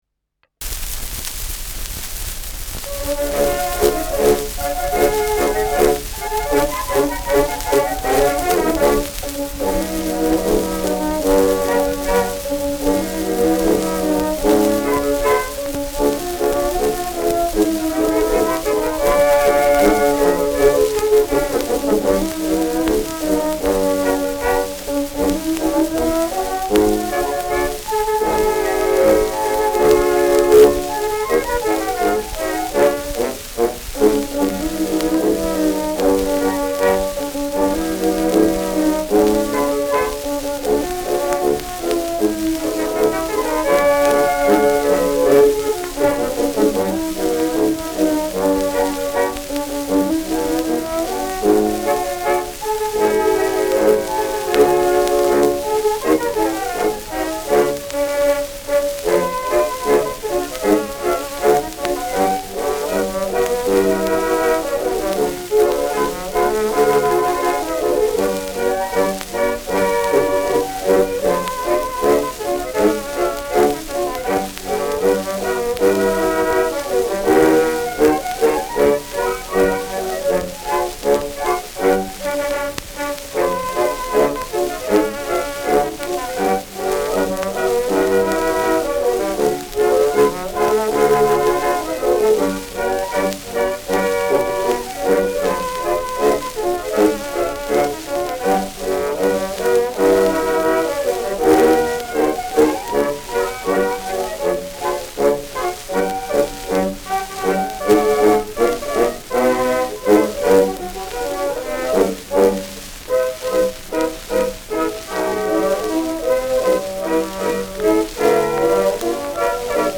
Schellackplatte
[Aufnahme circa 1910] [Publikation zwischen 1919 und 1921]